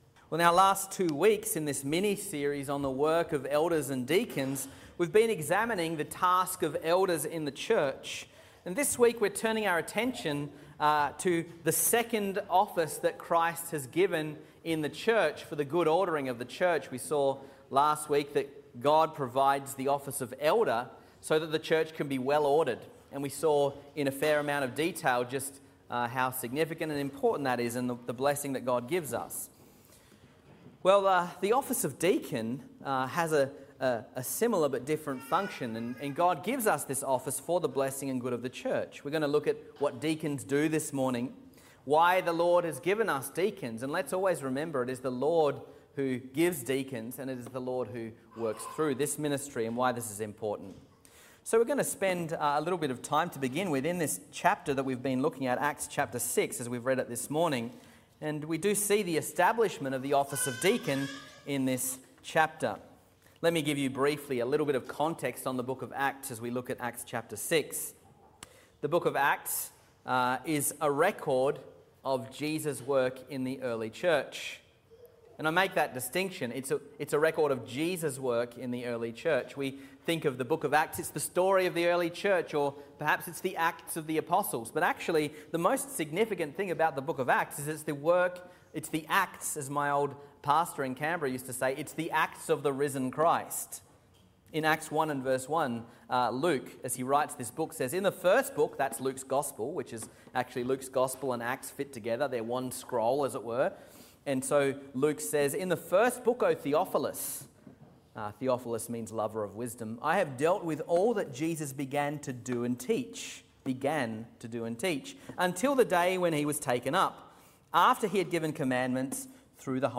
Sermons | Reformed Church Of Box Hill
Morning Service